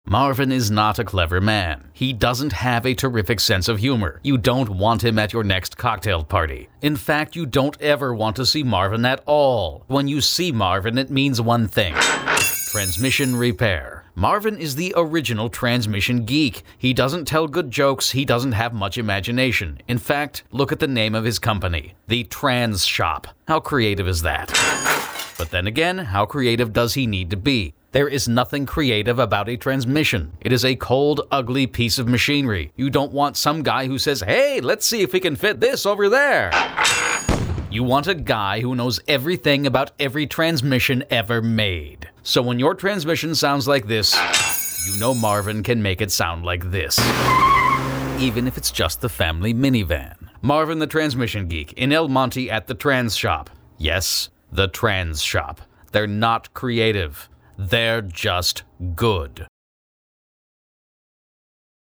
another Mercury winner from 2006, for a transmission shop.